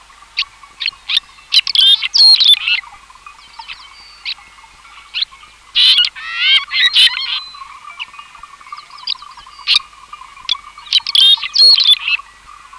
Red-rumped Swallow
Red-rumped-Swallow.mp3